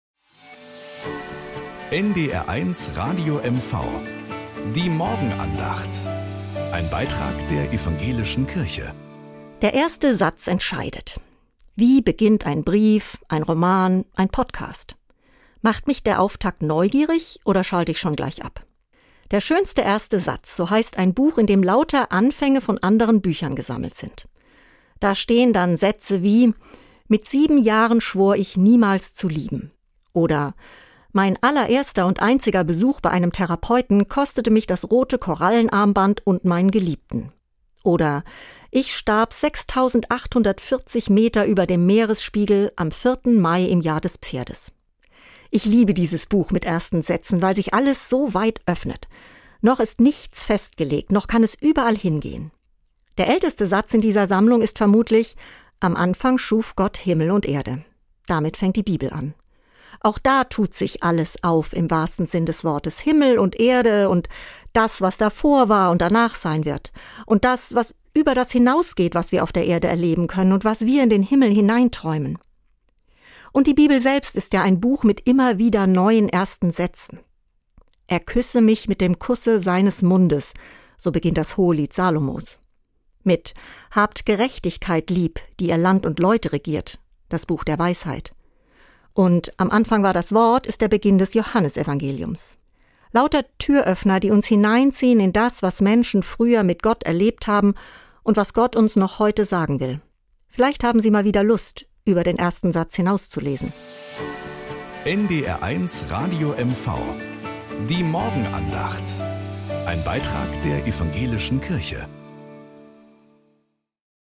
Um 6:20 Uhr gibt es in der Sendung "Der Frühstücksclub" eine Morgenandacht. Evangelische und katholische Kirche wechseln sich dabei ab.